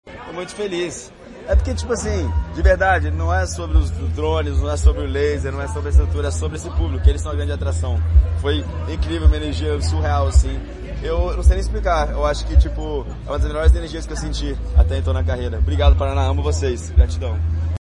Sonora do DJ Alok sobre a apresentação no Verão Maior Paraná